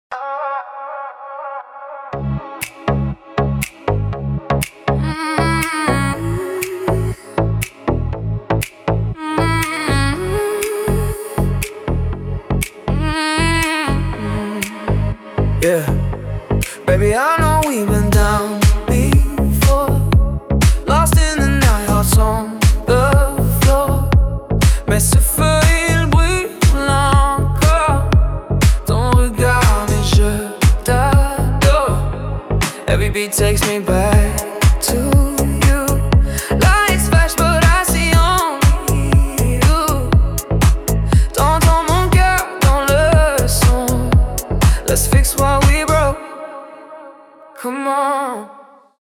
танцевальные
дуэт